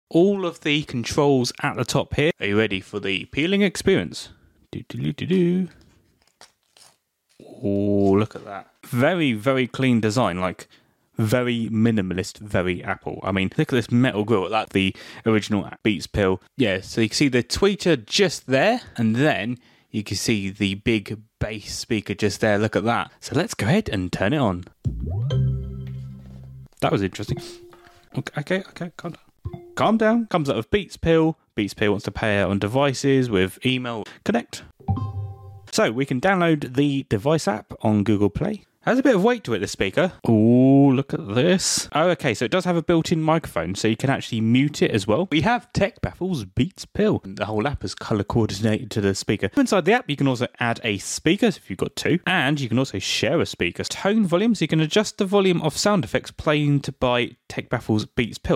Beats Pill ✨ - Powering On